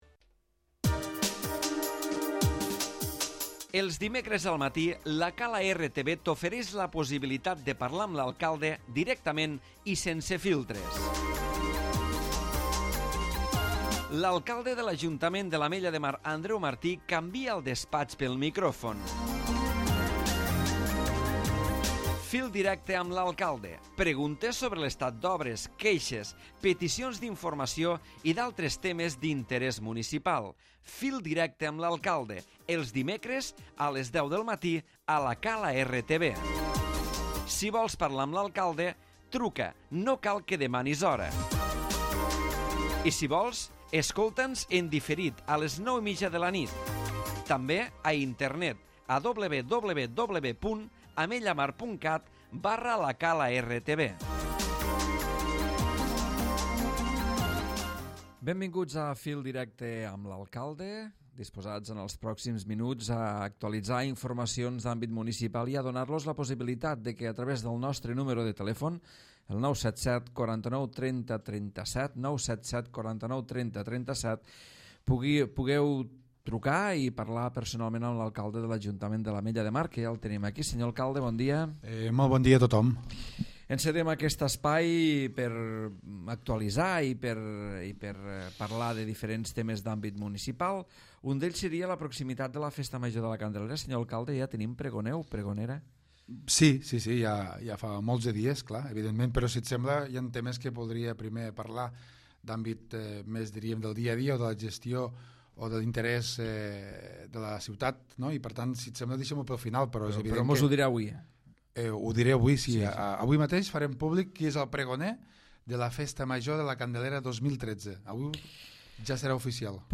L'Alcalde Andreu Martí ha parlat avui al Fil directe del Tiquet Fresc, del servei d'ambulància als malalts crònics, de les entrevistes de treball per a la residència de la Gent Gran i Centre de Dia i de la pregonera de la Candelera 2013 que serà Carme Forcadell, presidenta de l'Assemblea Nacional Catalana.